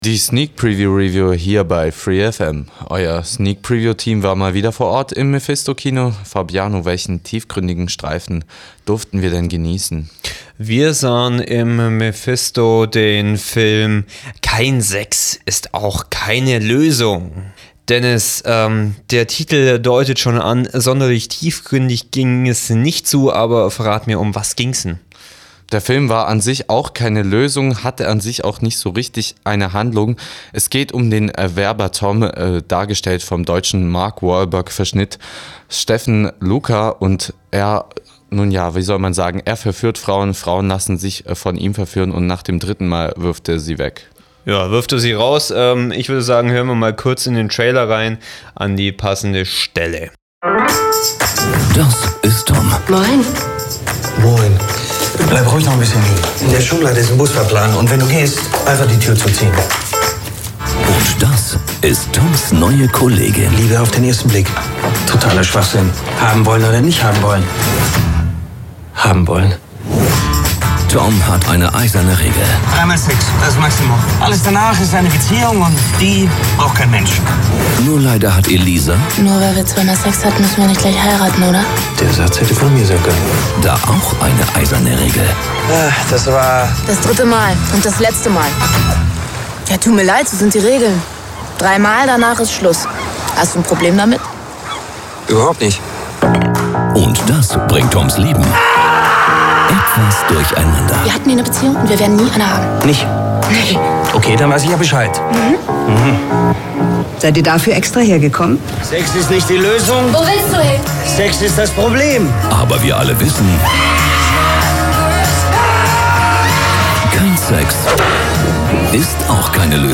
sneak_preview_review_kein_sex_ist_auch_keine_loesung_0.mp3